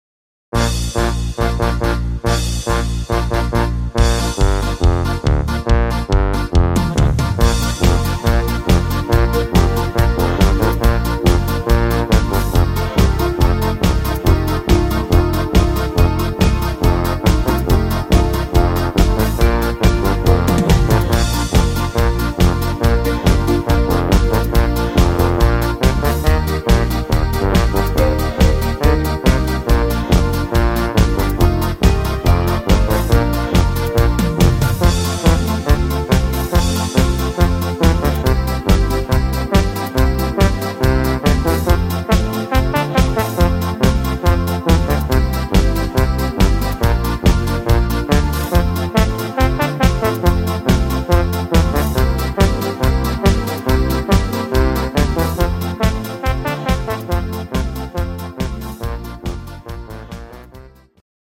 Playback abmischen  Playbacks selbst abmischen!
Rhythmus  Beat Polka
Art  Deutsch, Volkstümlich